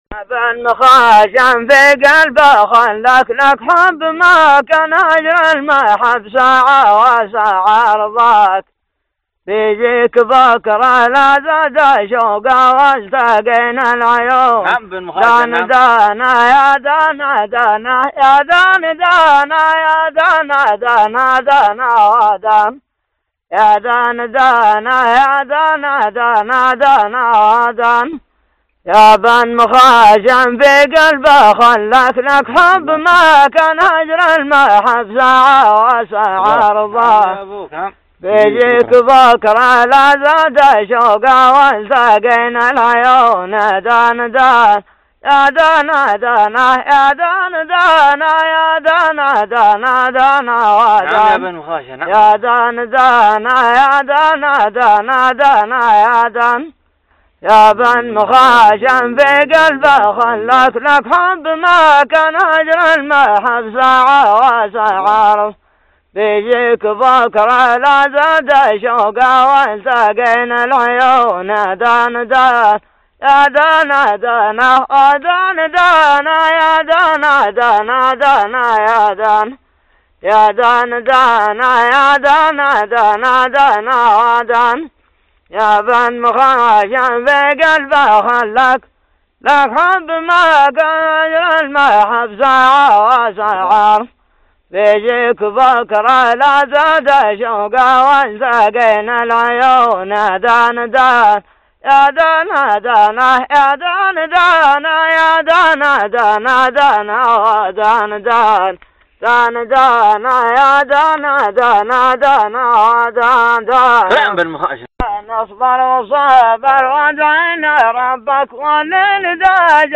لحن ( دان )
شكرا للمشرف العام على رفع الملف استمتعنا بسماع الصوت العذب ..